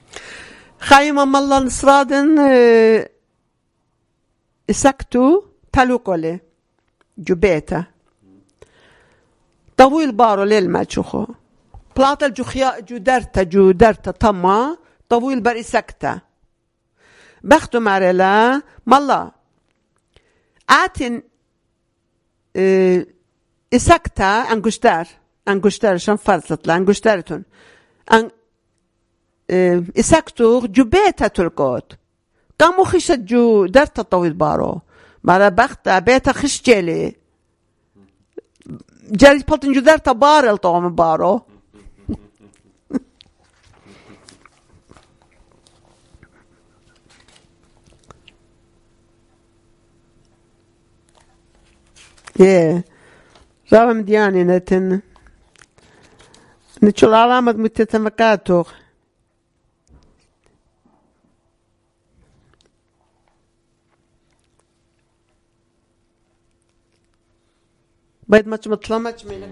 Urmi, Christian: A Lost Ring